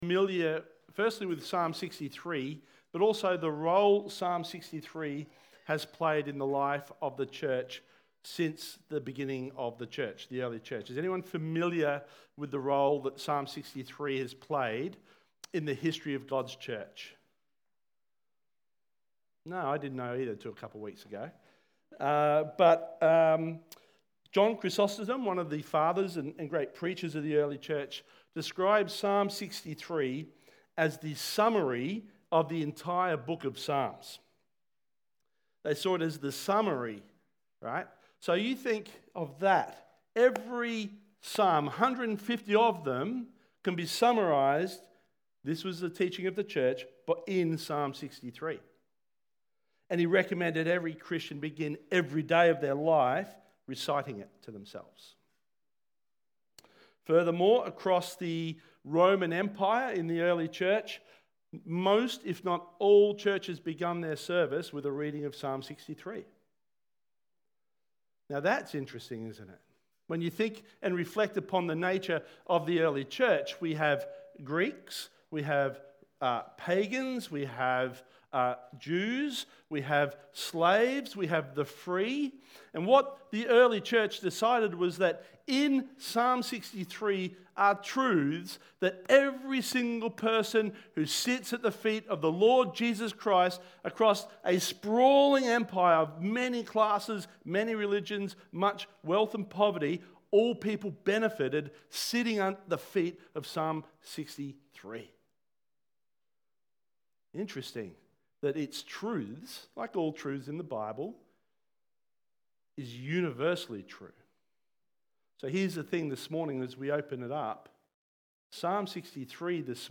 Church-Sermon-010226.mp3